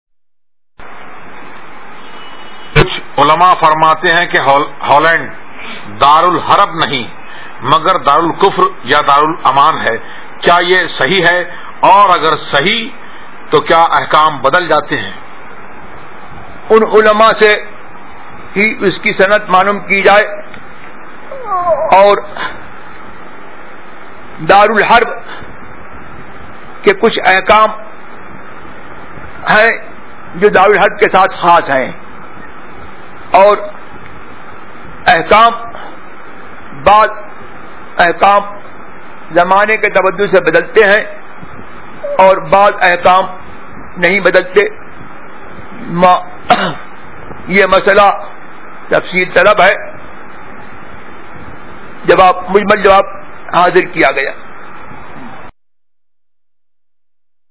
Answer (Voice Recording):